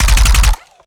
GUNAuto_RPU1 Burst_03_SFRMS_SCIWPNS.wav